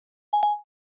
Звук Siri голосового помощника при готовности дать ответ